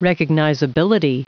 Prononciation du mot recognizability en anglais (fichier audio)
Prononciation du mot : recognizability